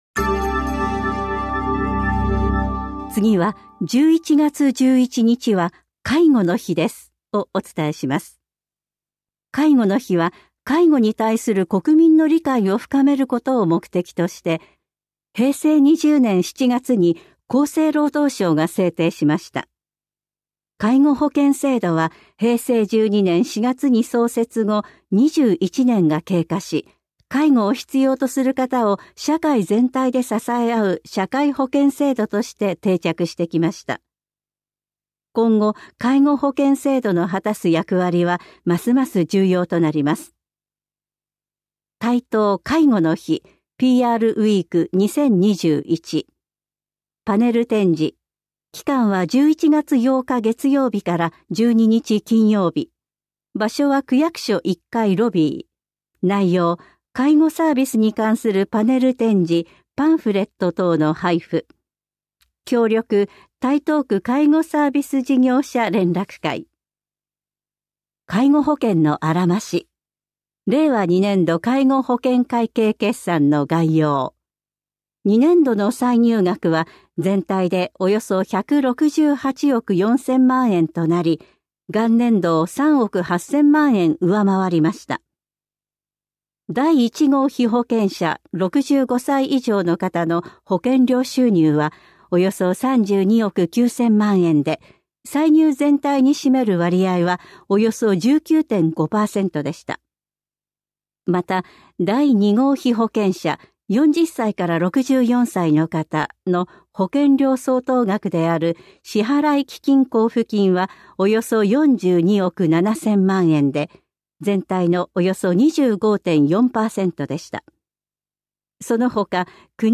広報「たいとう」令和3年10月20日号の音声読み上げデータです。